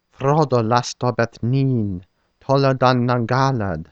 Elvish Line, click on it to hear the line being spoken by a voice actor.
/ˈfrɔ.dɔ   ˈlas.tɔ   ˈbɛθ   ˈni:n/   /ˈtɔ.lɔ   dan   naŋ   ˈga.lad/